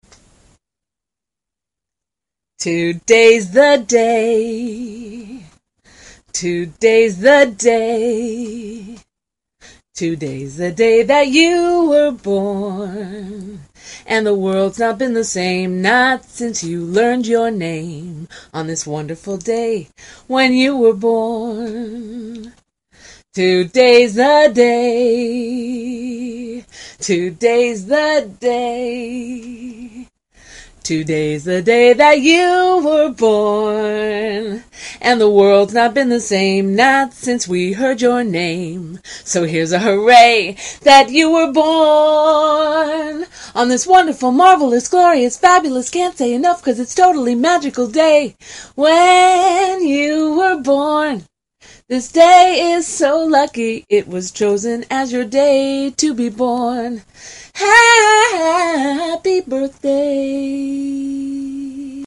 An original "Happy Birthday" song which has been hastily recorded on a hand-held digital recorder so I can send it to my friends on their special day.